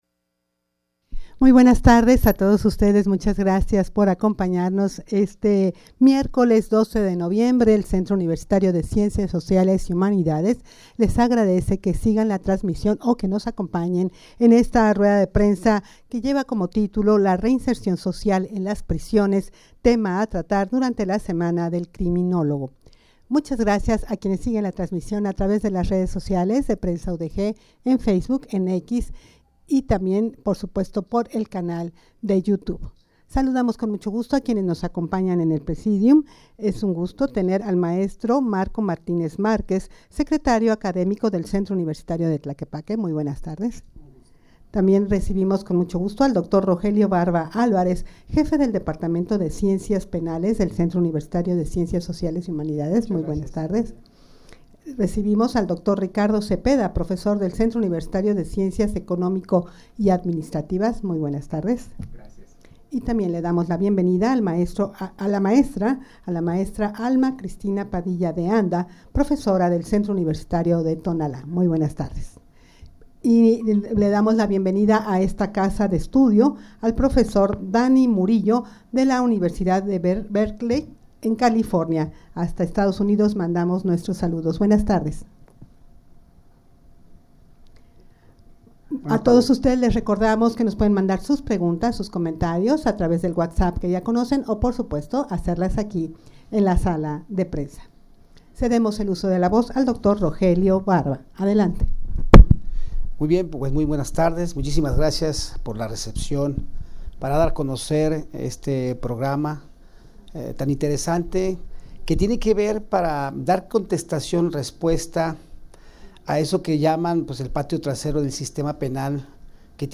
Audio de la Rueda de Prensa
rueda-de-prensa-la-reinsercion-social-en-las-prisiones-tema-a-tratar-durante-la-semana-del-criminologo.mp3